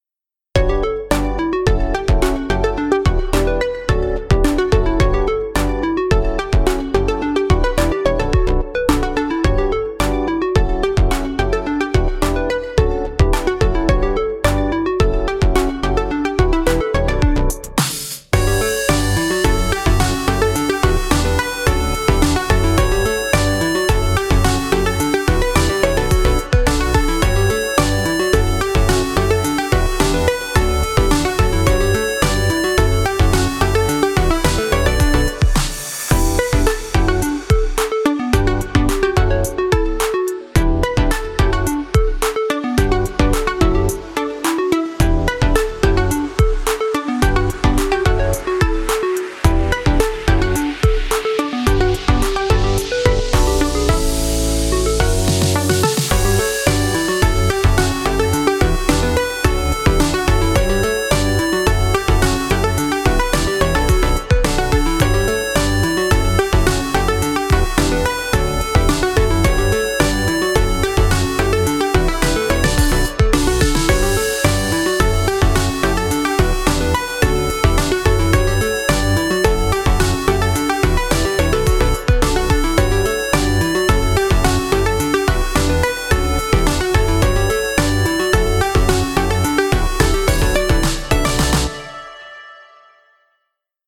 明るい雰囲気かわいいEDM風ミュージックです。